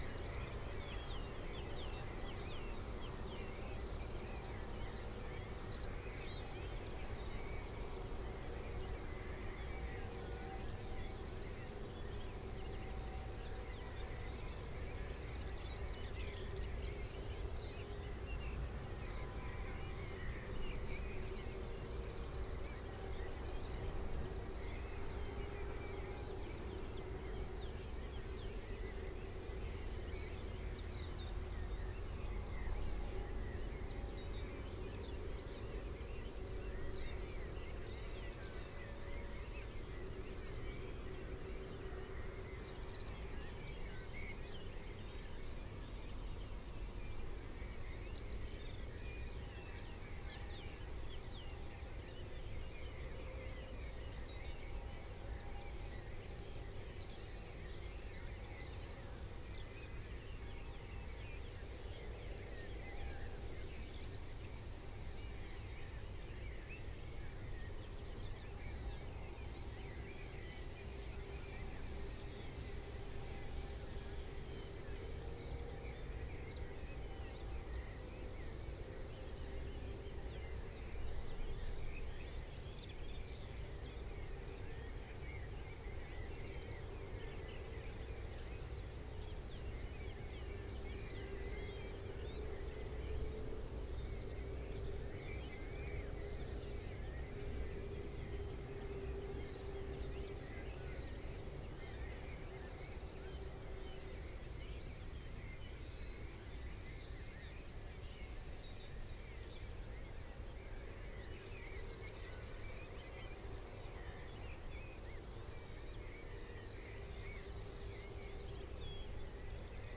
Columba palumbus
Corvus corone
Fringilla coelebs
Turdus philomelos
Phylloscopus collybita
Erithacus rubecula
Alauda arvensis